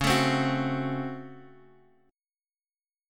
DbM7sus4#5 chord